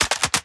GUNMech_Insert Clip_03_SFRMS_SCIWPNS.wav